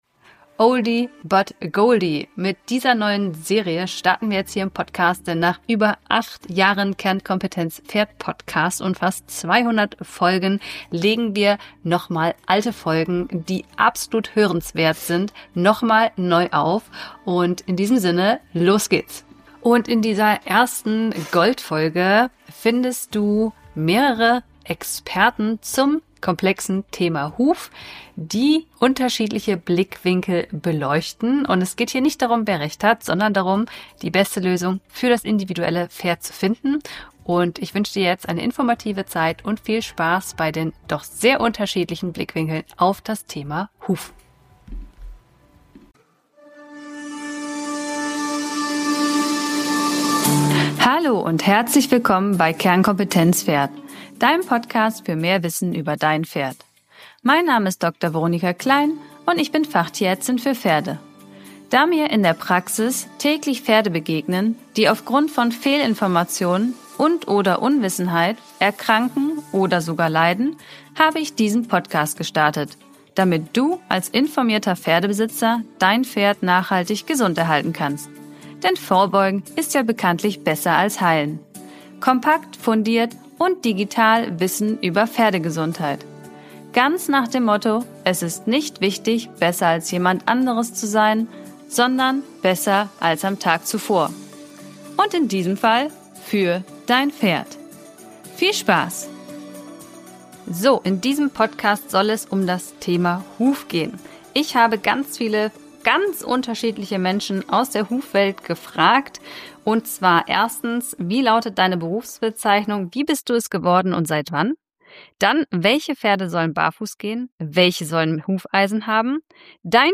Daher legen wir Gold-Folgen nochmal neu auf, denn auch wenn sie nicht neu aufgenommen wurden – ihr Inhalt ist aktueller denn je.